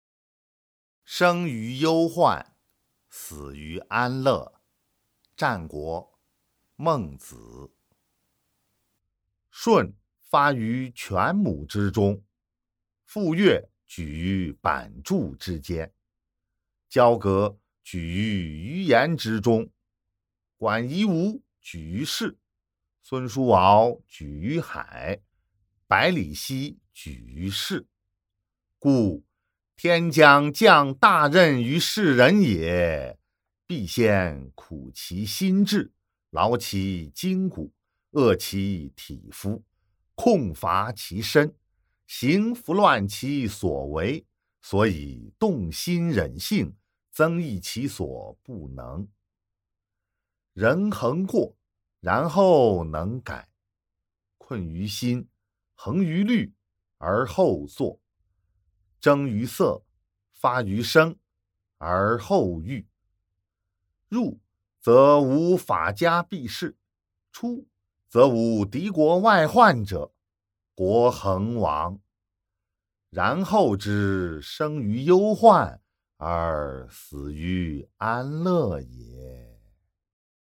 《孟子》生于忧患，死于安乐（读诵）